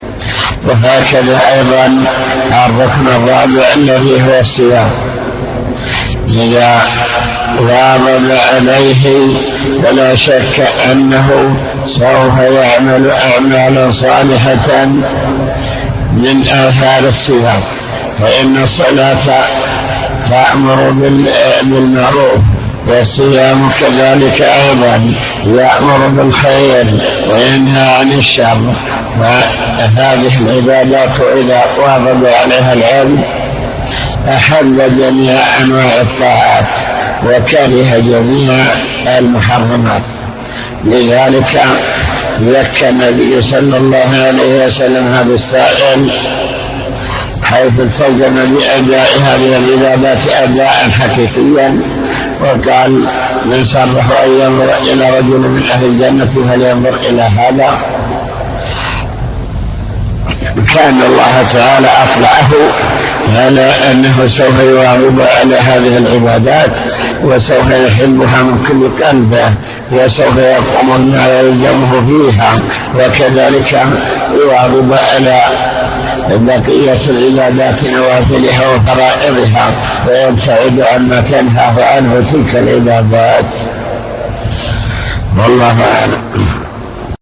المكتبة الصوتية  تسجيلات - كتب  شرح كتاب بهجة قلوب الأبرار لابن السعدي شرح حديث قل آمنت بالله ثم استقم بشارة الله لمن آمن واستقام على طريقه